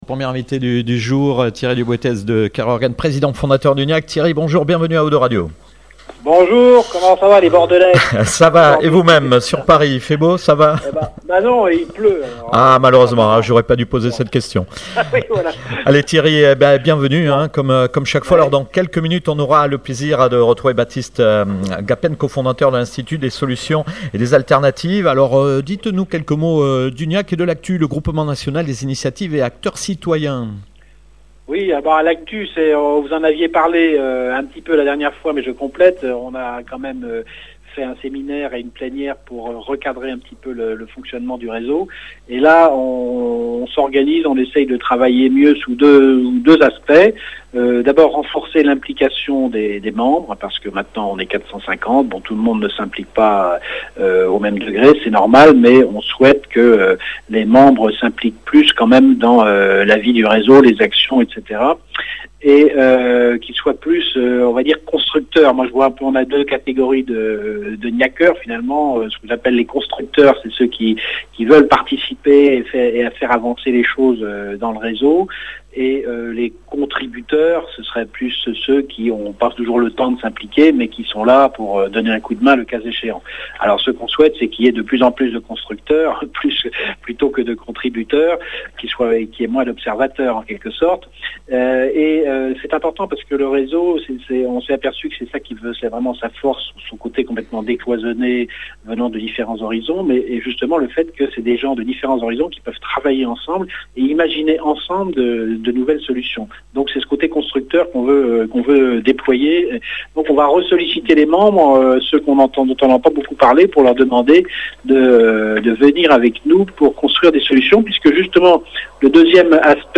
10/11/2016 : Nouvelle interview initiatives GNIAC / O2 Radio